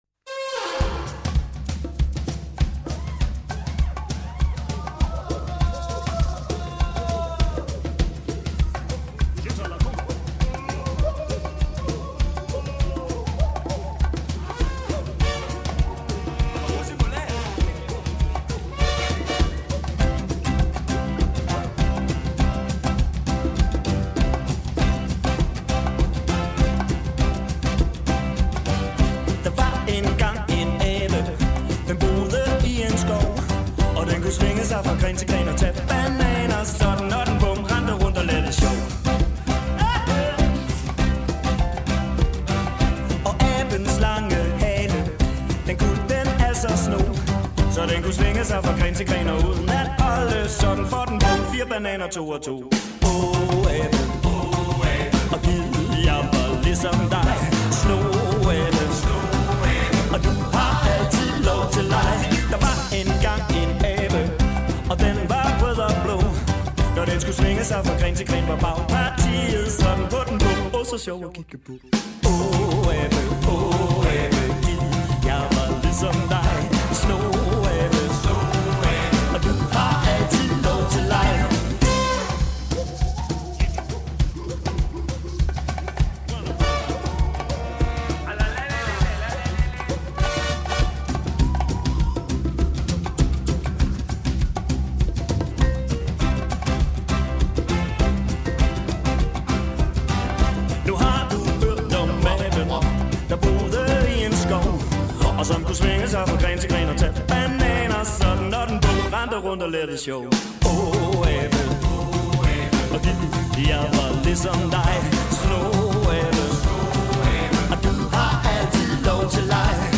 • Originale børnesange
trio Rock'n'roll med højt humør